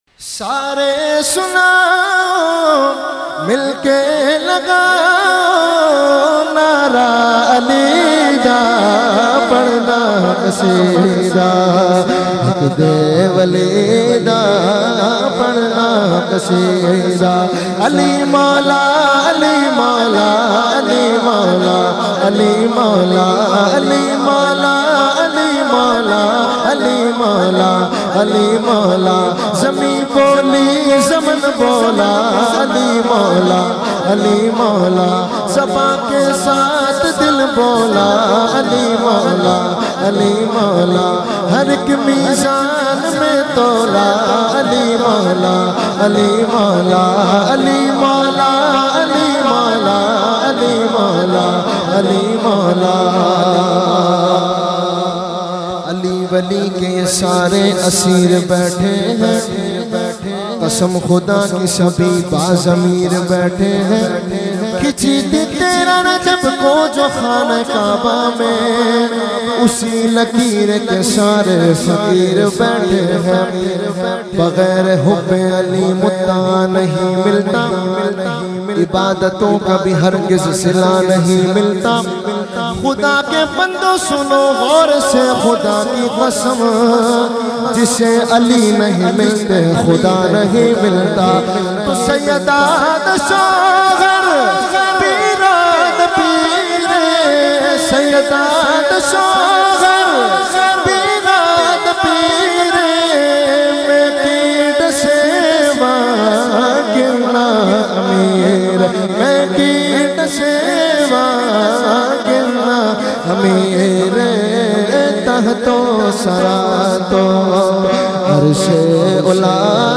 Category : Manqabat | Language : SiraikiEvent : Muharram 2020